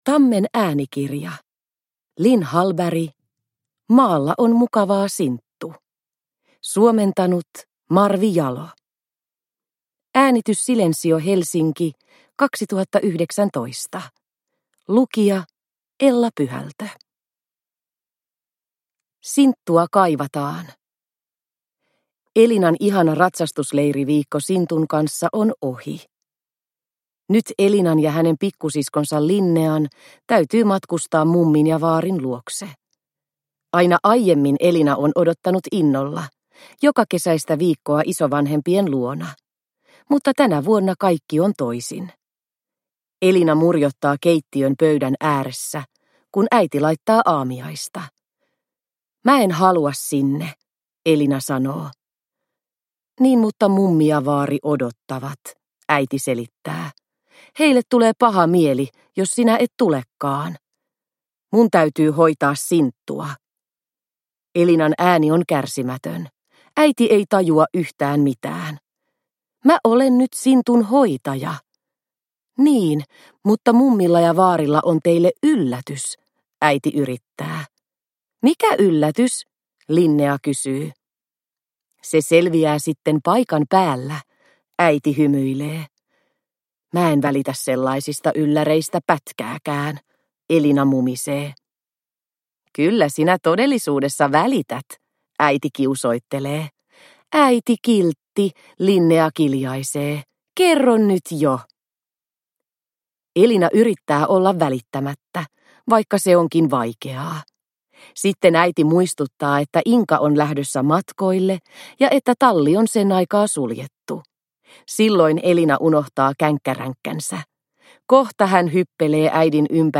Maalla on mukavaa, Sinttu – Ljudbok – Laddas ner